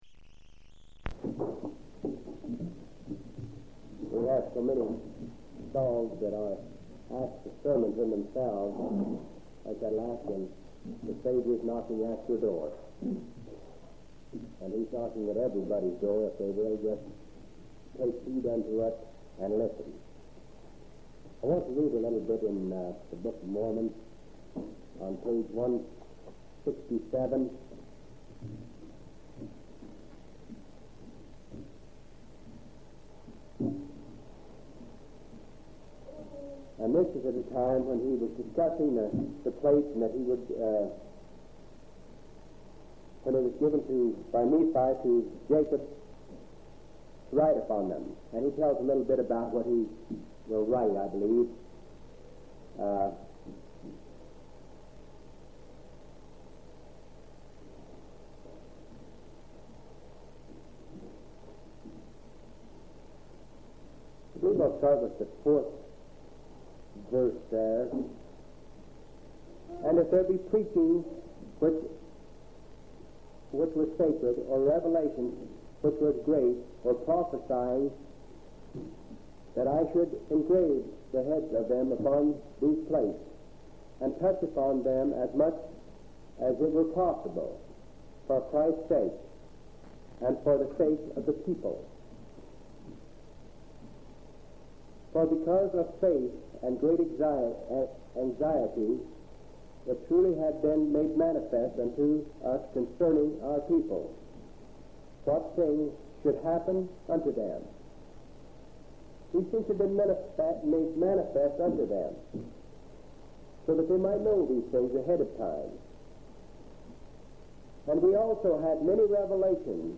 8/21/1977 Location: Grand Junction Local Event